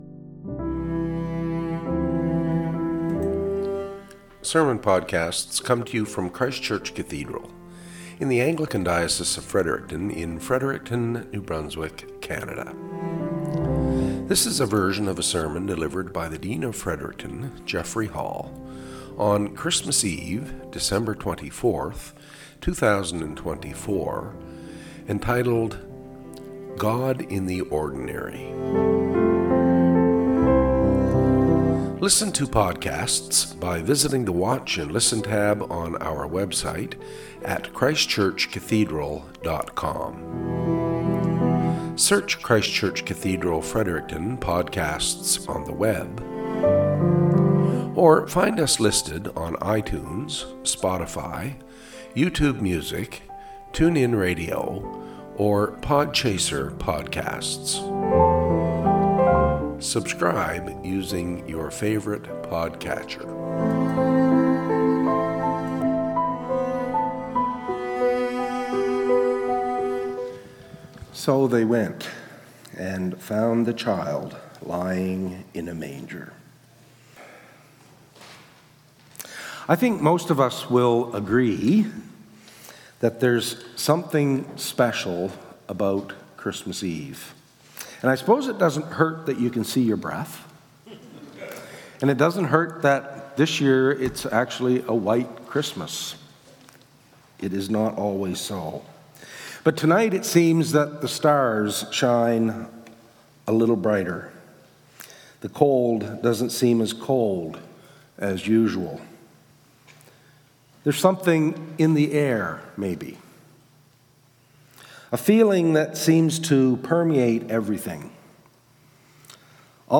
SERMON - "God in the Ordinary"